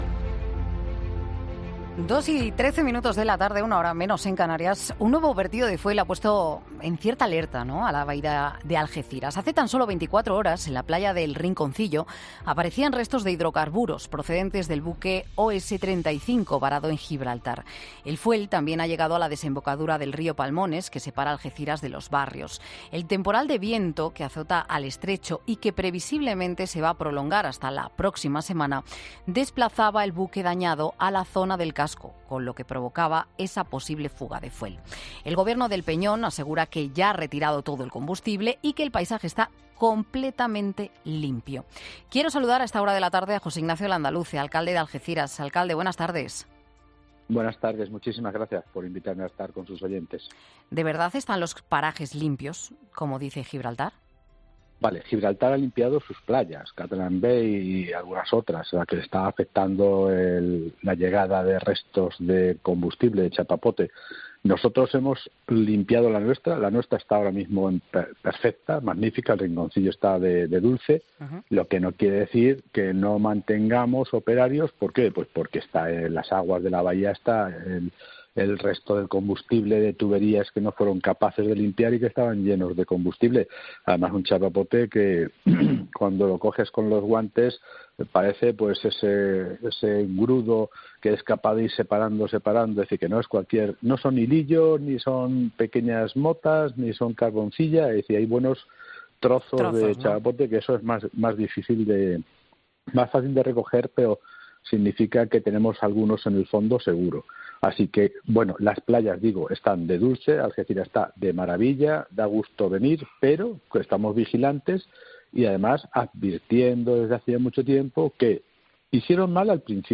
José Ignacio Landaluce, alcalde de Algeciras, explica que ha pasado con el fuel del OS35 en Mediodía COPE